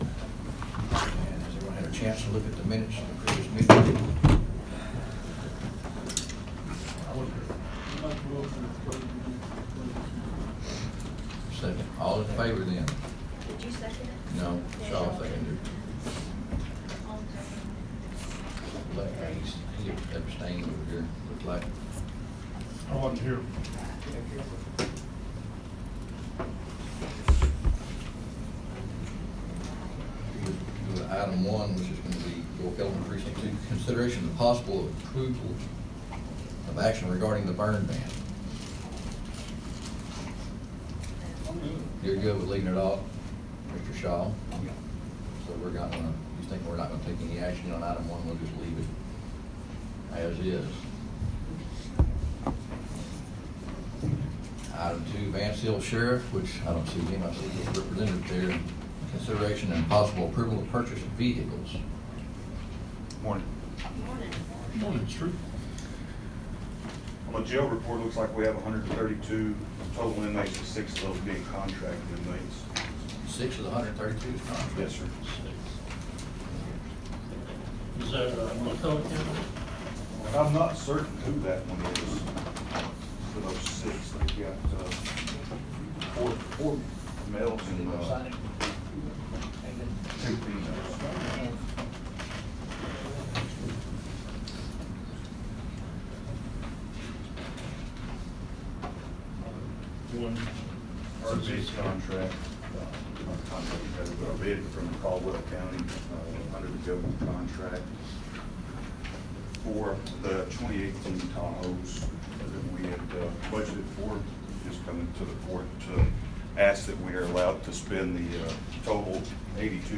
The Brown County Commissioners Court was held at 9 a.m. at the Brown County Courthouse on January 2, 2018 to discuss agenda items which included a proposal to continue a contract with Bob Turner Rural Consulting.